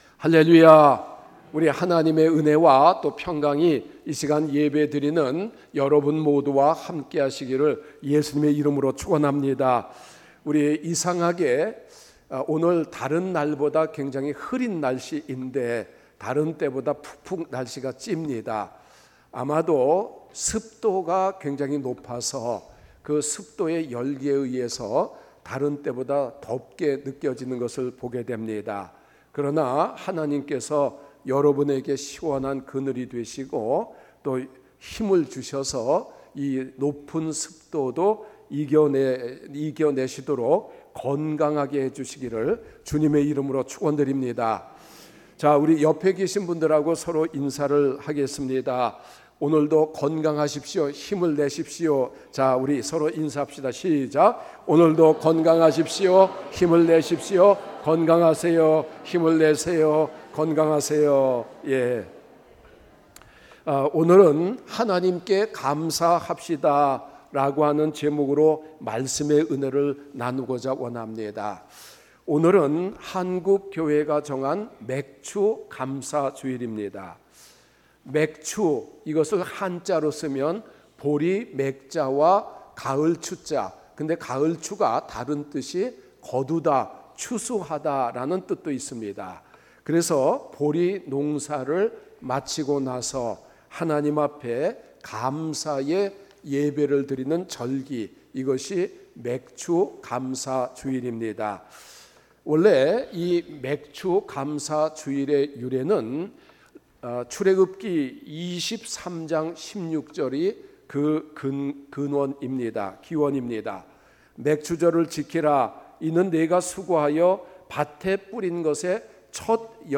주일설교